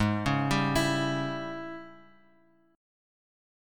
AbM7sus4#5 Chord